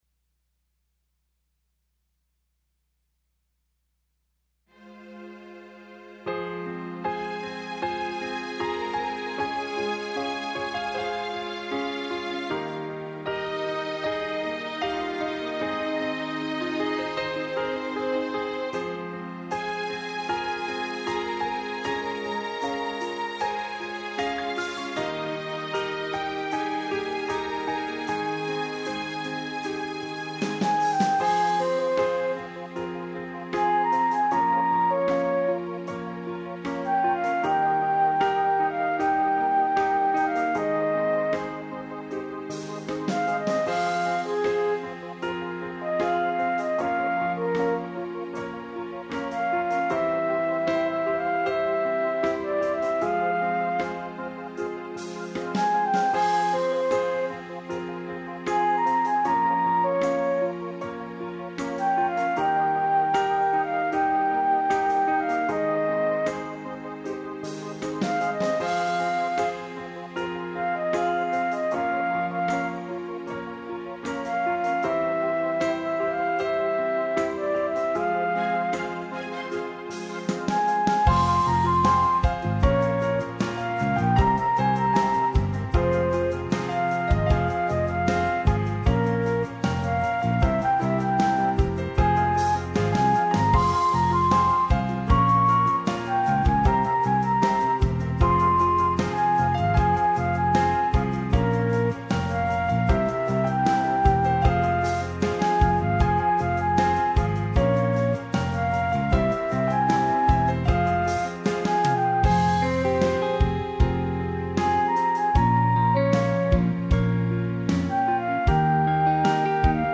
Зарождение поп-песни (ближе к балладе)
Вокальную партию пока исполняет флейта (мидийная). Настроение она передает крайне поверхностно и звучит как-то по детски, но на данном этапе это все чем располагаем. Сведение трека сугубо символическое.